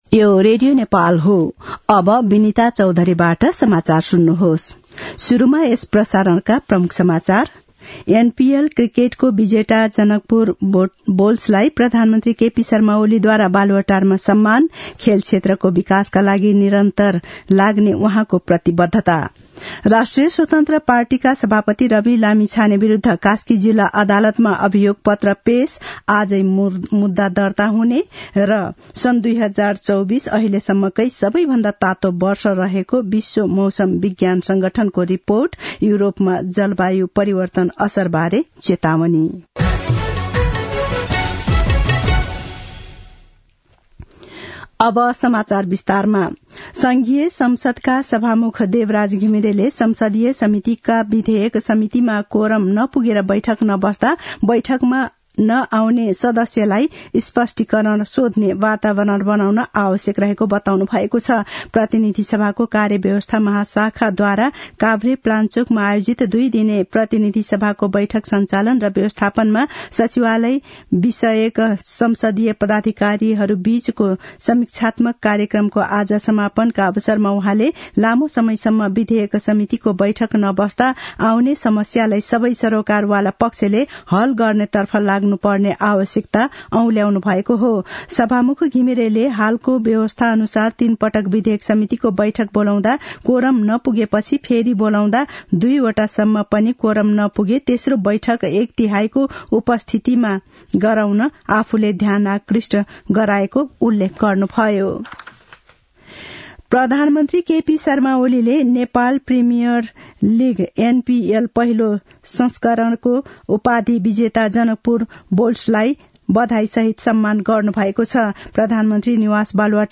दिउँसो ३ बजेको नेपाली समाचार : ८ पुष , २०८१
3-pm-nepali-news-1-12.mp3